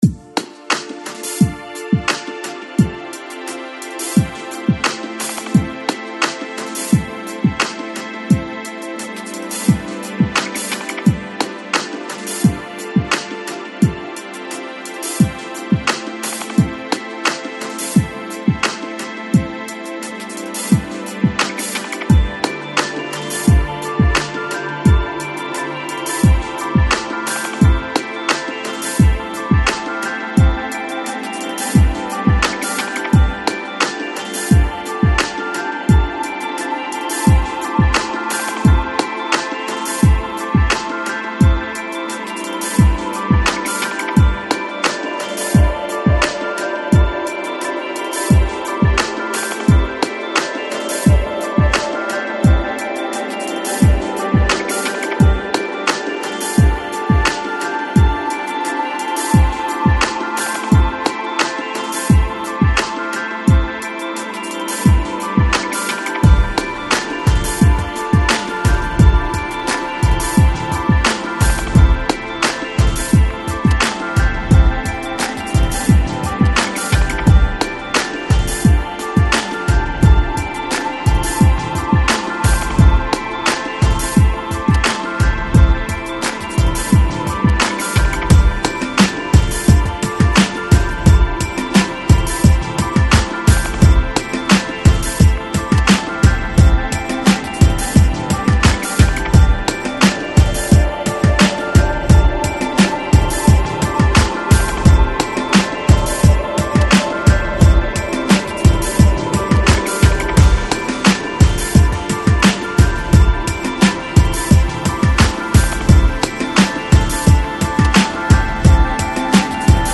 Chill Out, Lounge, Downtempo